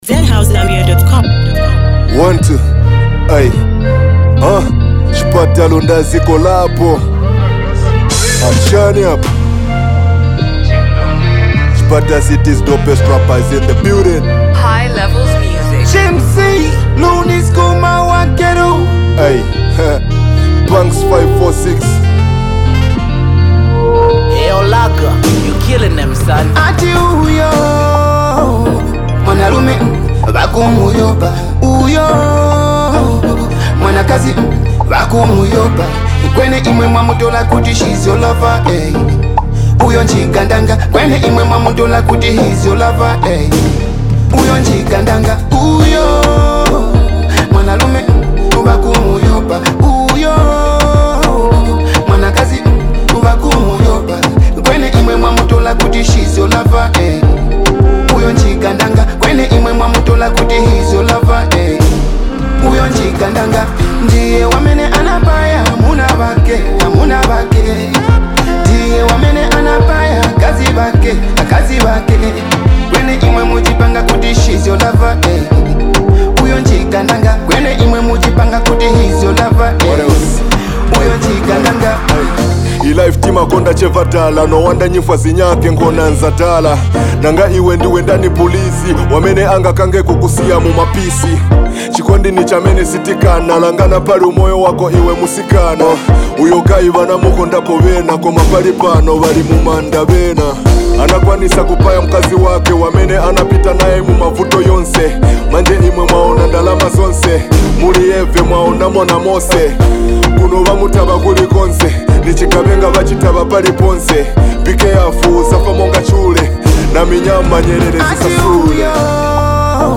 is a soulful track with a gripping storyline.